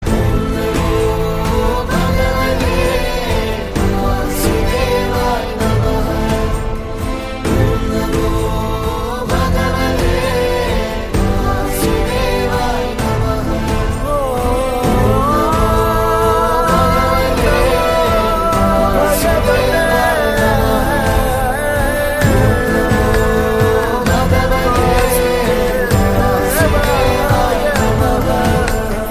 Devotional
Categories: Bhakti Ringtones